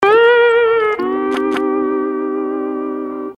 Spongebob Fail Sound Effect Free Download